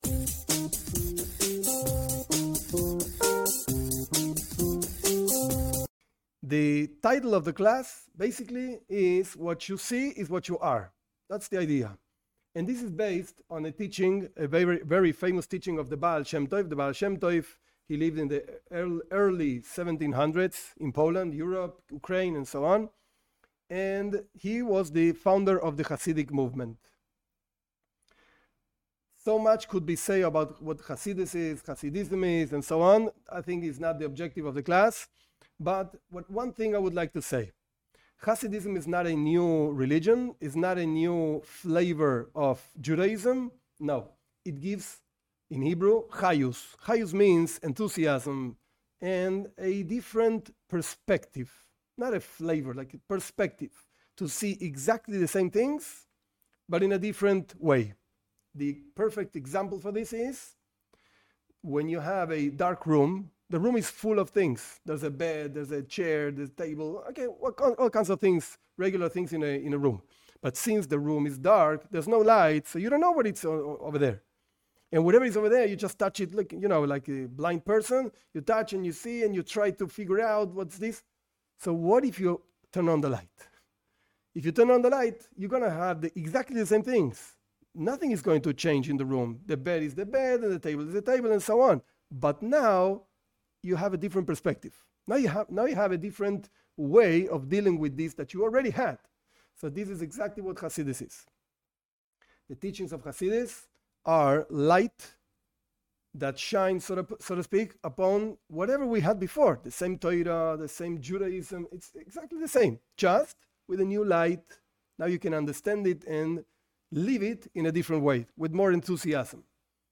This class explains a teaching by the Baal Shem Tov: when a person sees evil in others, it's a proof that you have that within yourself.